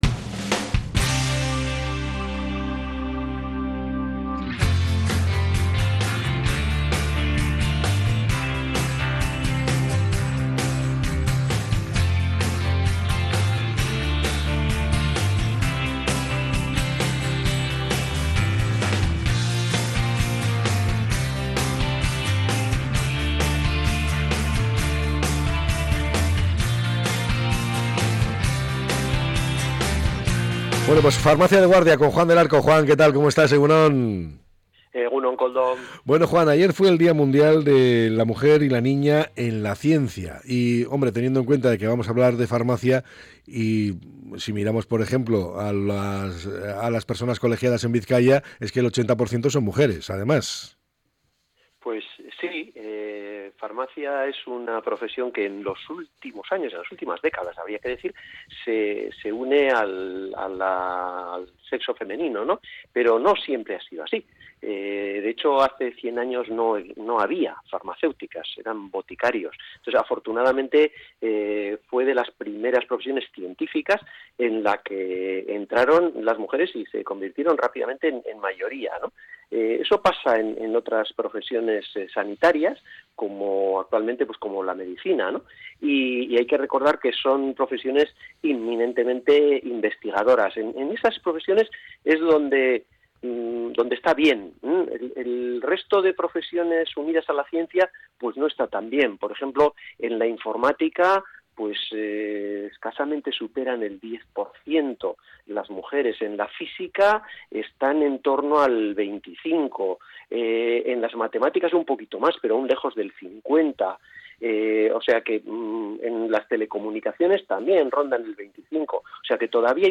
Durante la entrevista